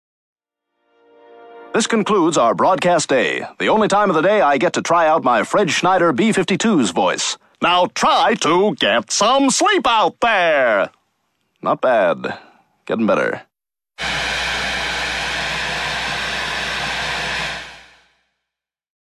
(static) Seth MacFarlane ( Tom Tucker )